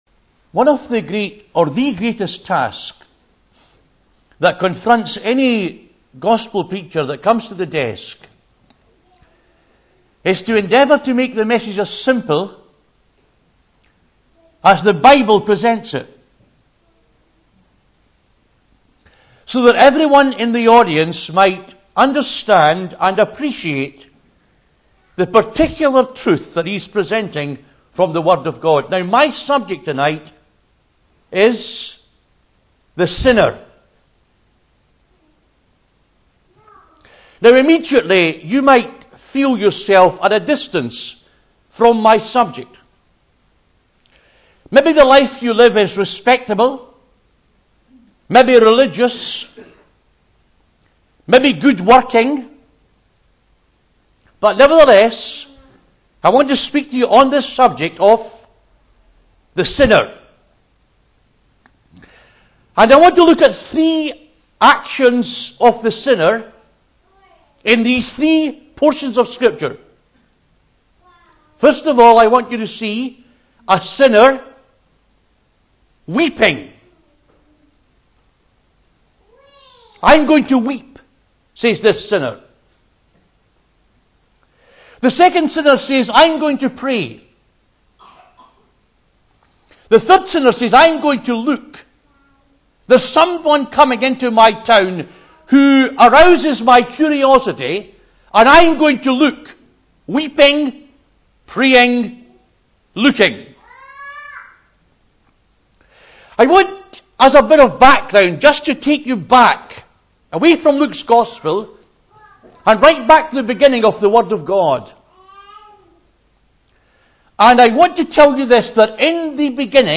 Easter Conference 2015 – GOSPEL
The great problem of sin and its only remedy in Christ are stressed with clarity and earnestness.